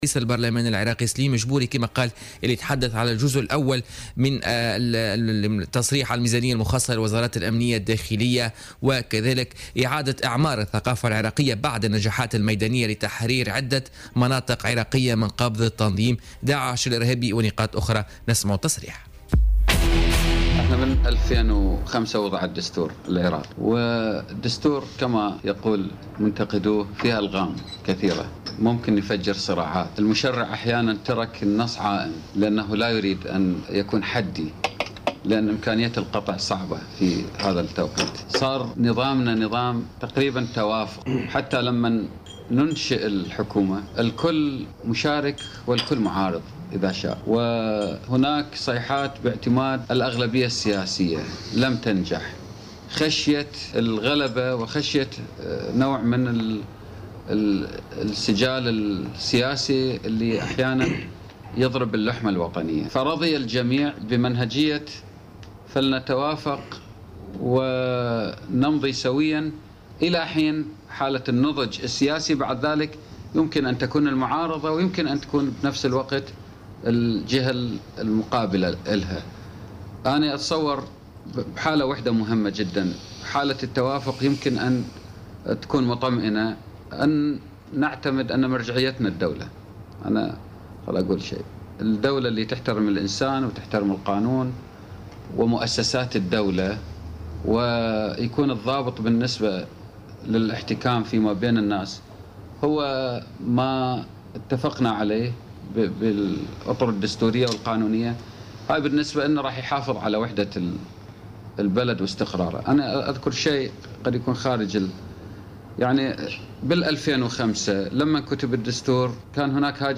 وفي رده على سؤال مراسلنا حول توزيع ميزانية الدولة العراقية وأولوياتها، قال رئيس البرلمان العراقي إن هناك صعوبات في الميزانية حيث تعتبر تشغيلية بالأساس ويتم تخصيص مواردها لنفقات الرواتب أساسا.